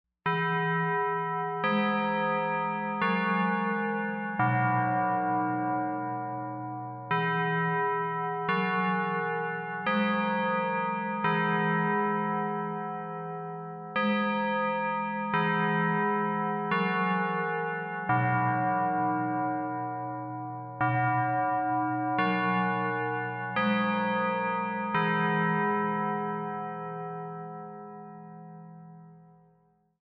Remember the peal of Westminster chimes from Gunter Hall, UNC’s iconic central campus building?
The campus ringtone still plays every 15 minutes from Gunter Hall.
gunter-bell-chimes.mp3